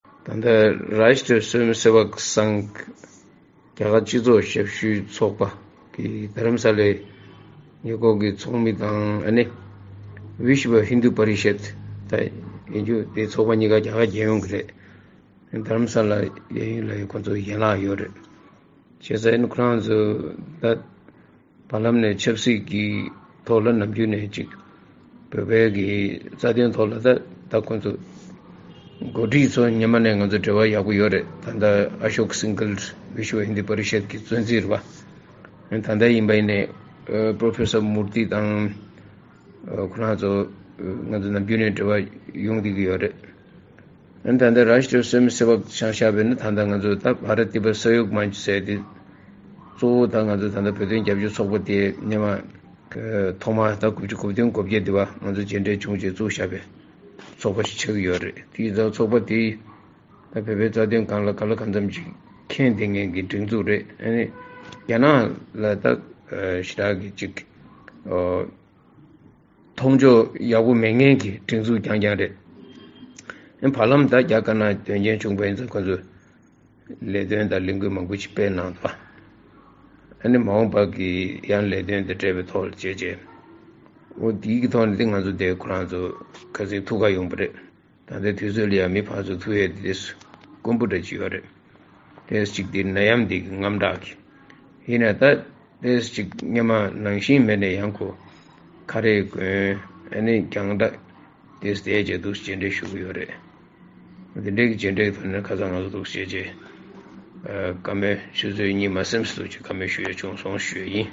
སྤྱི་འཐུས་ཚོགས་གཞོན་མཆོག་ལ་ད་རེས་ཀྱི་མཇལ་མོལ་འདིའི་སྐོར་བཀའ་འདྲི་ཞུས་པ།
སྒྲ་ལྡན་གསར་འགྱུར།